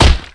Kick8.wav